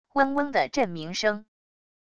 嗡嗡的震鸣声wav音频